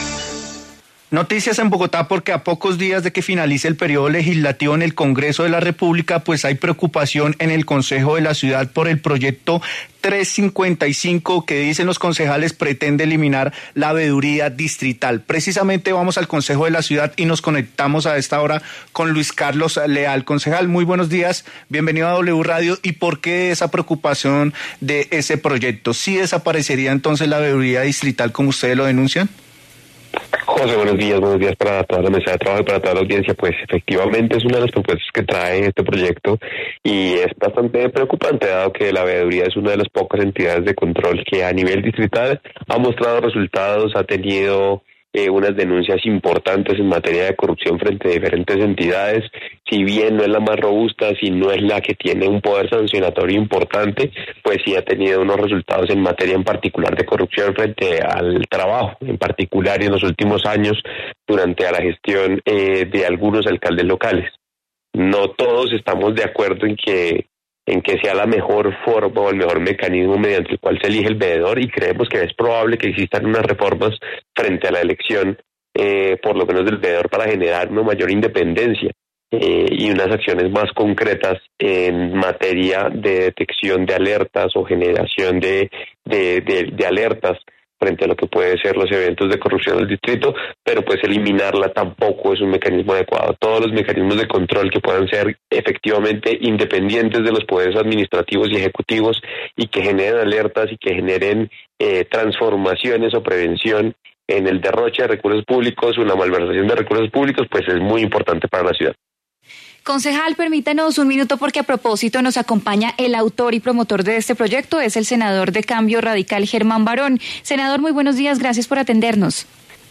En La W, el concejal Luis Carlos Leal y el congresista Germán Varón se pronunciaron sobre el proyecto que preocupa al Concejo de Bogotá porque buscaría eliminar la veeduría distrital.